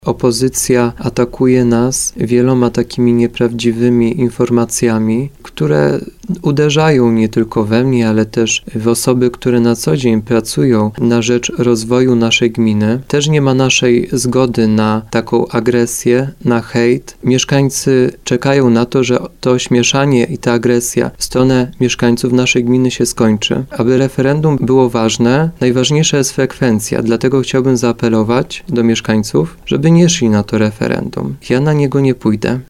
Burmistrz Radłowa Mateusz Borowiec apeluje do mieszkańców, aby nie szli w niedzielę na referendum. – Dość politycznej agresji i ośmieszania naszej gminy – mówił na antenie RDN Małopolska.